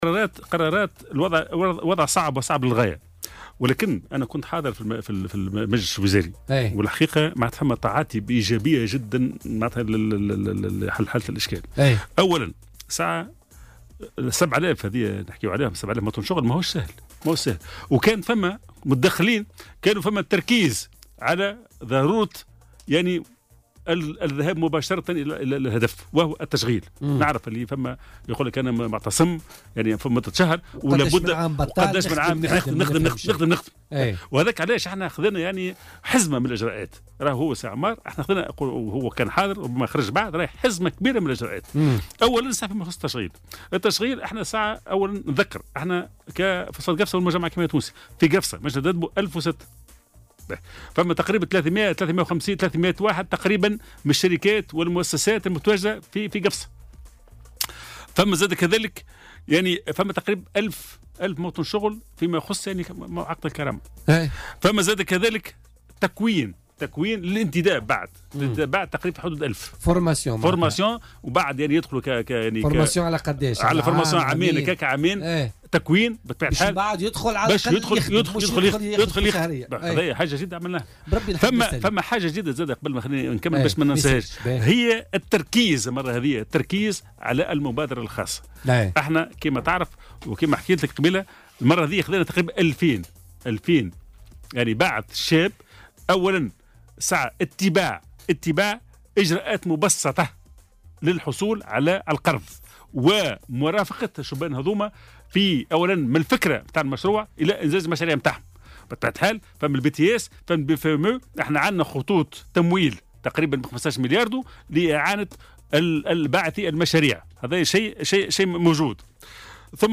Invité ce mardi de l'émission Politica sur Jawhara FM